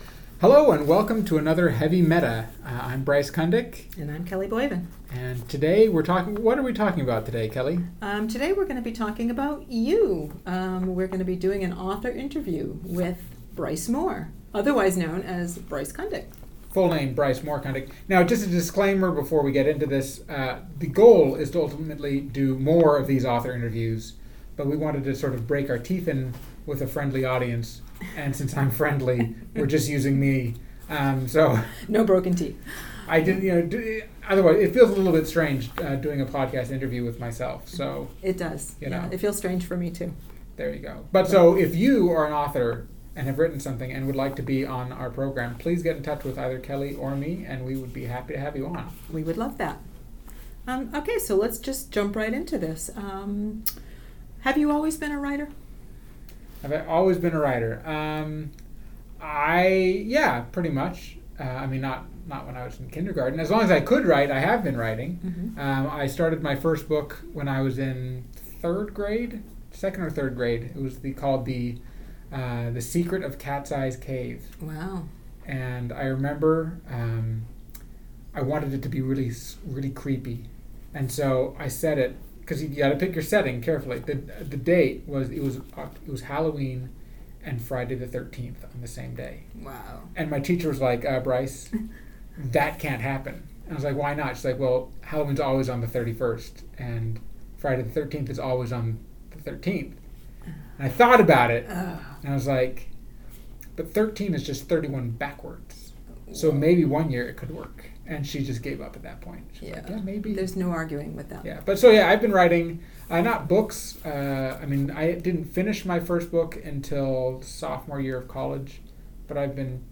The first in their author interviews series.